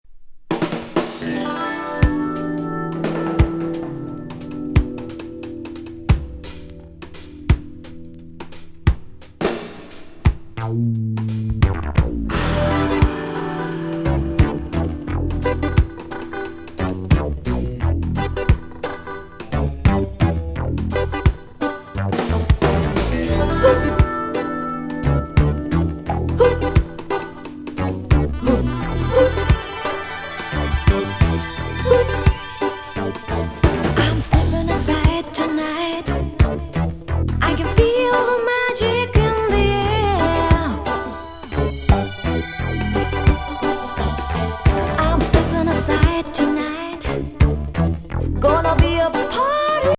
LA録音82年作。
＆ダビーなレゲー・ナンバー